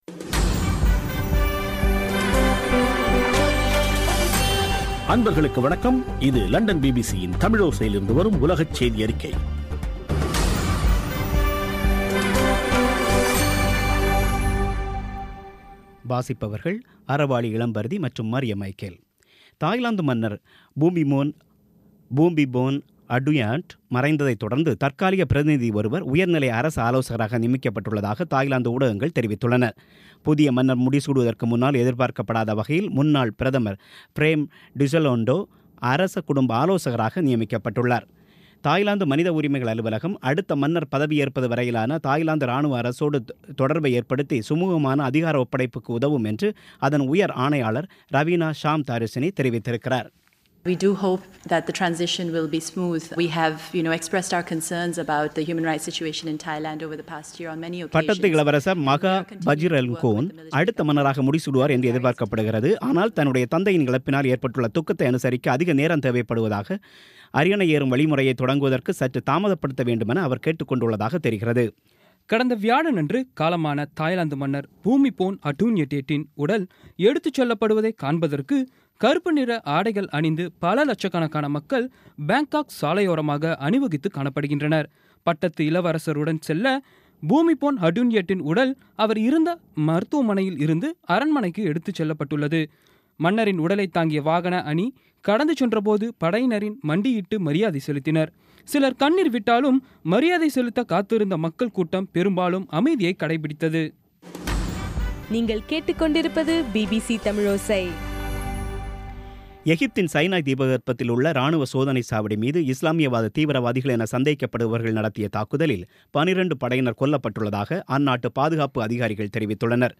இன்றைய (அக்டோபர் 14ம் தேதி) பிபிசி தமிழோசை செய்தியறிக்கை